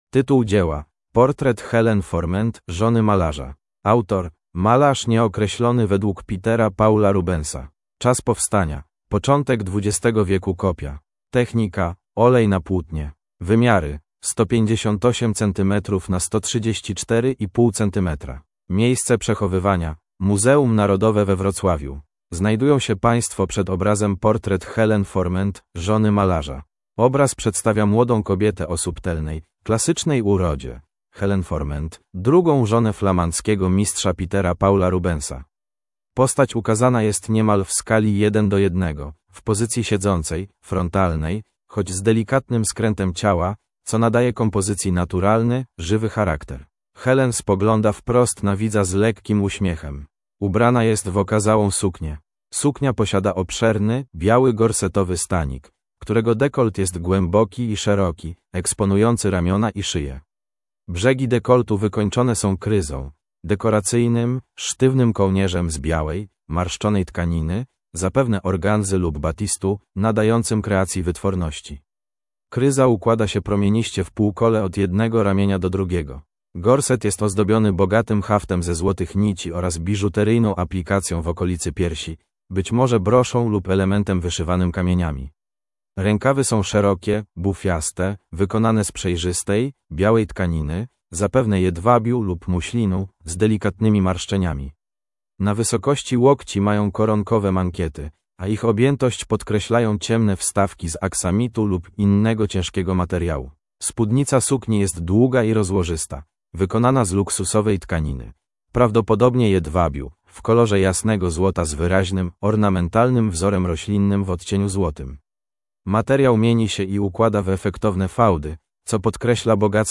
MNWr_audiodeskr_Portret_Helene_Fourment_zony_malarza.mp3